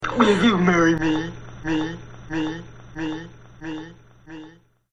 Will You Marry Me Vocal